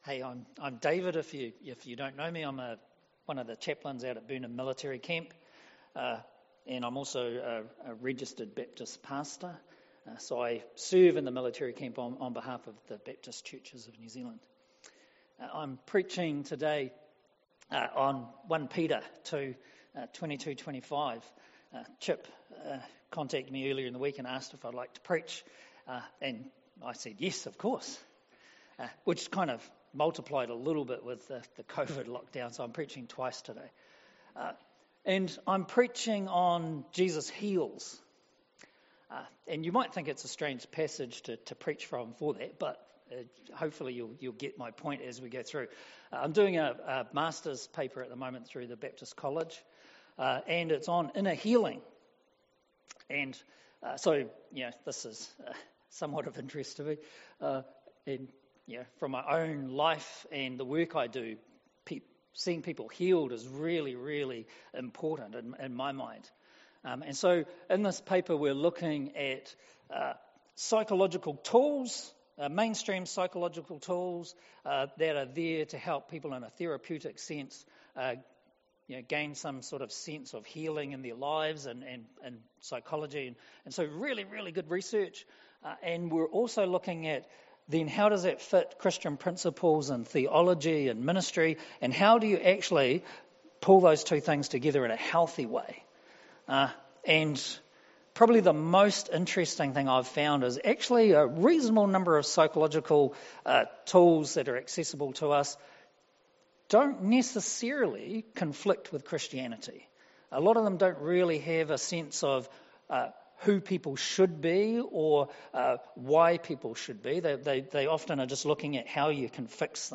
Sermons | Lincoln Baptist Church